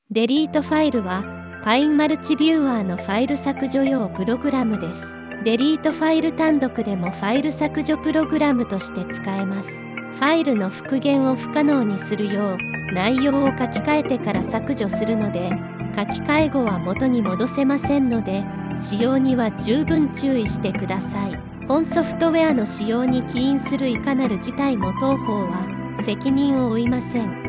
Voice紹介
VOICEVOX Nemo DeleteFile0.85.mp3